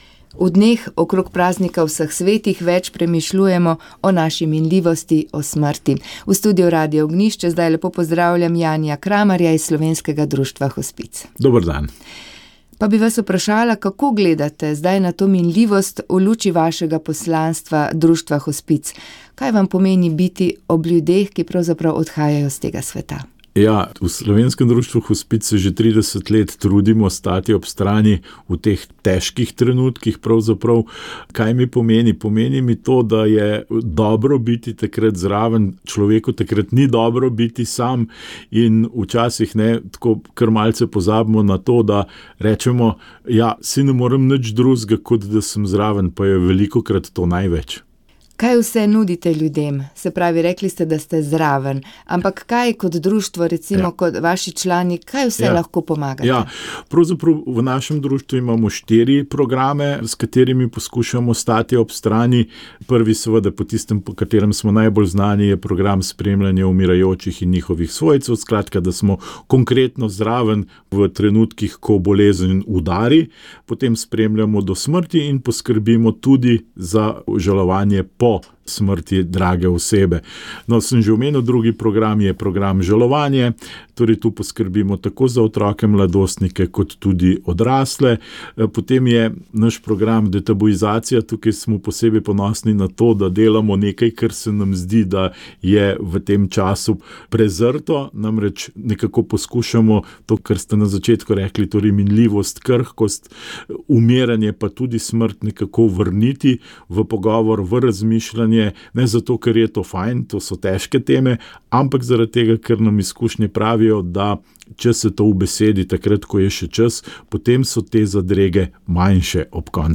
Med letošnjimi zlatomašniki bi bil tudi nadškof Alojz Uran, a ga je Gospod na veliko soboto poklical k sebi. V njegovi rojstni župniji so se pripravljali na zlato mašo, ki bi morala biti danes. Namesto tega so se dopoldne v šmarski cerkvi Sv. Martina zahvalili za dar njegovega življenja in duhovništva.